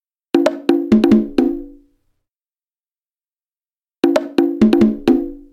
邦戈和刚果
描述：打击乐
Tag: 130 bpm Fusion Loops Percussion Loops 954.23 KB wav Key : C